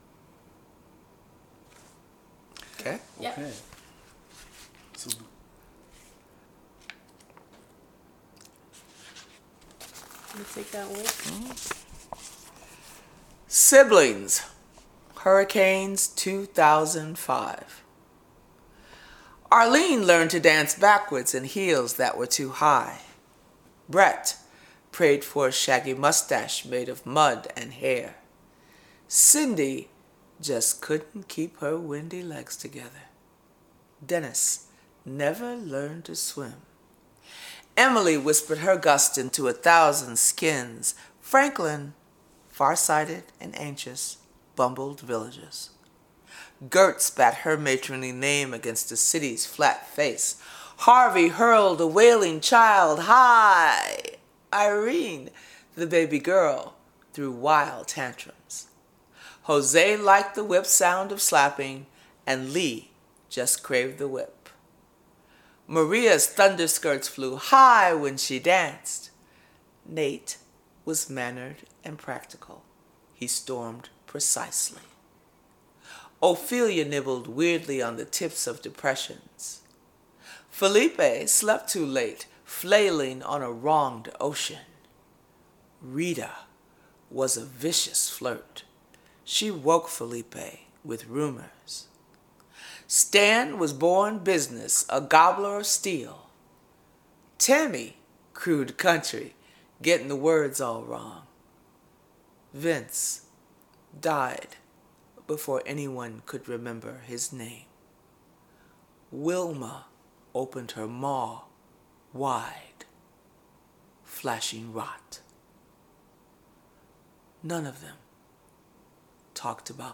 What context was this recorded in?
Here the poem Siblings by . We visited her in her home in New Jersey for a research about . The hurrican literature is part of this large stream in the environmental literature.